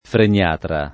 freniatra
vai all'elenco alfabetico delle voci ingrandisci il carattere 100% rimpicciolisci il carattere stampa invia tramite posta elettronica codividi su Facebook freniatra [ fren L# tra ] s. m. e f. (med.); pl. m. -tri